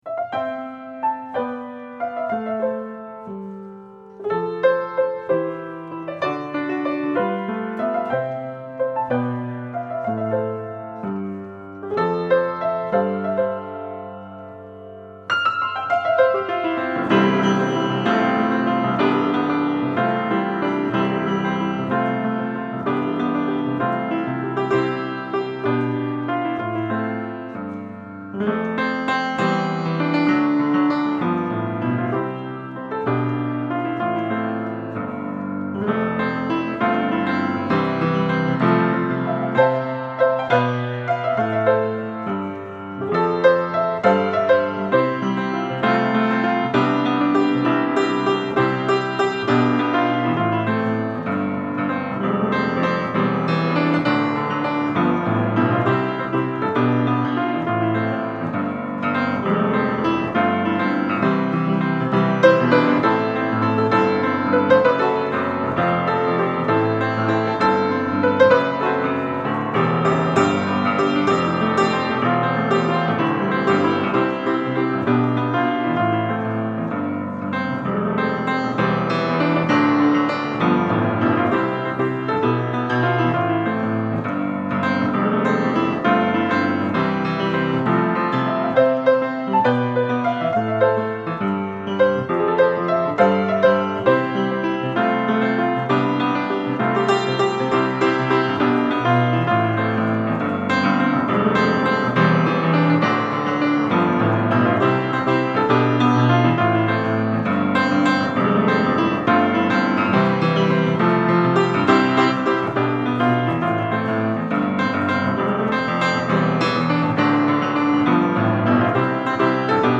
Easy Listening
Cocktail Music
Piano Jazz , Solo Piano